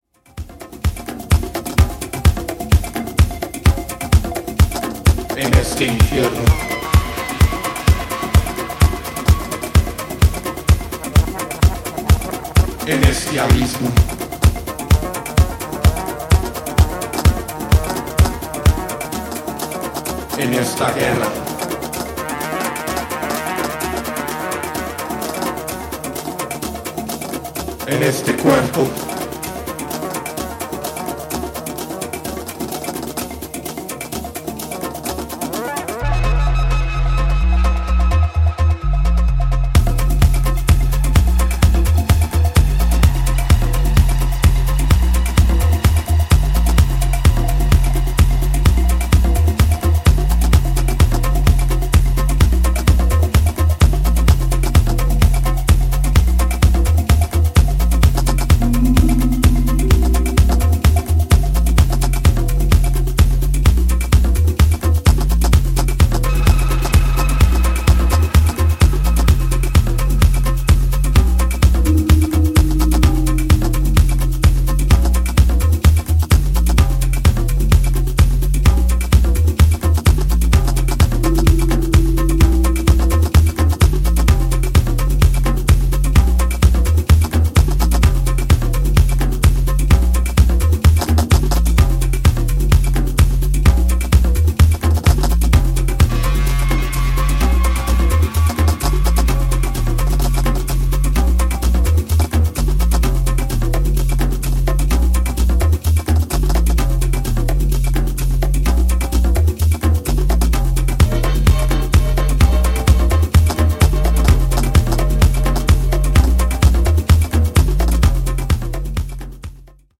Genre Techno